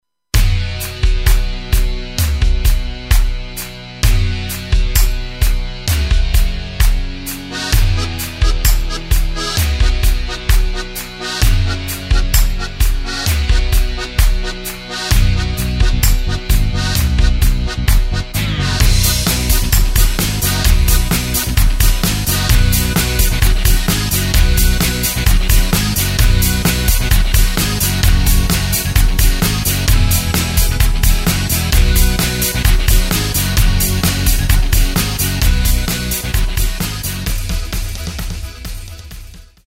Takt:          4/4
Tempo:         130.00
Tonart:            C
Playback mp3 mit Lyrics